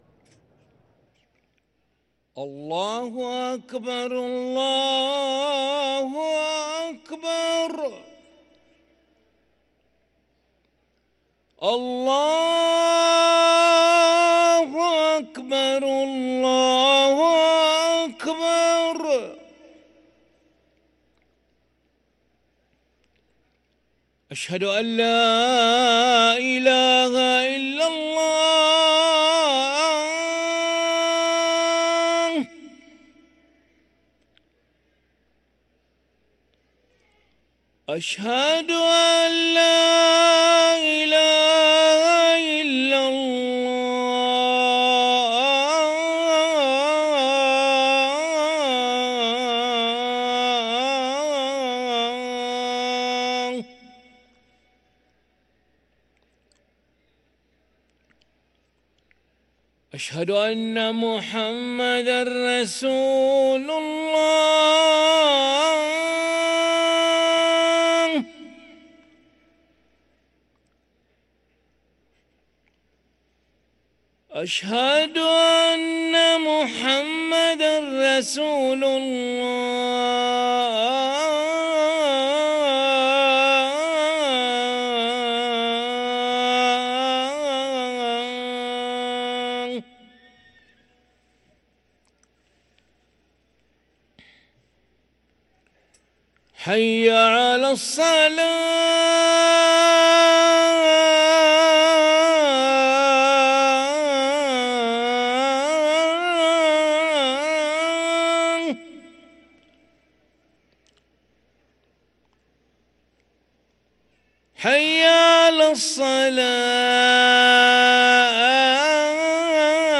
أذان العشاء للمؤذن علي ملا الأحد 21 رجب 1444هـ > ١٤٤٤ 🕋 > ركن الأذان 🕋 > المزيد - تلاوات الحرمين